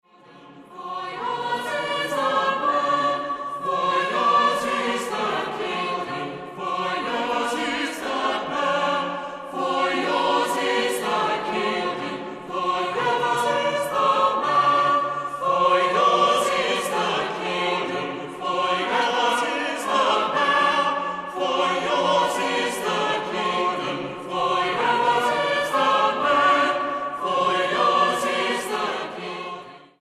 • Sachgebiet: Klassik: Geistliche Chormusik